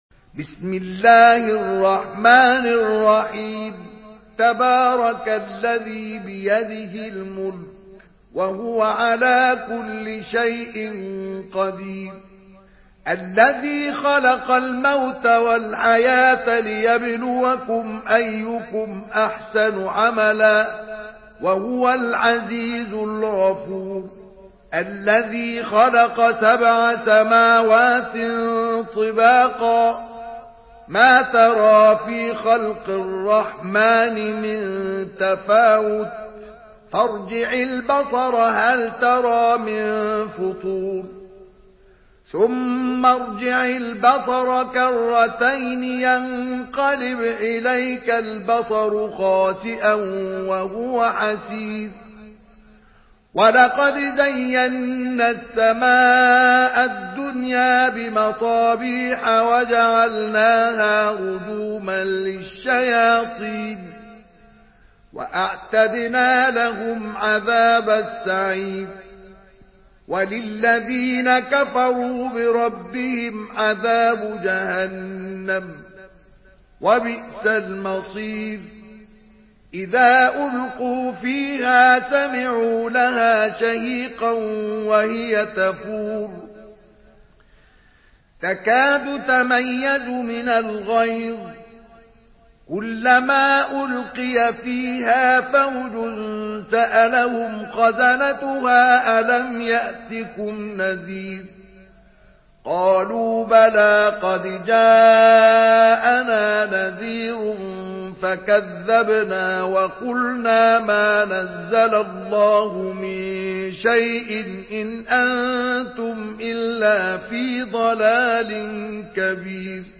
سورة الملك MP3 بصوت مصطفى إسماعيل برواية حفص
مرتل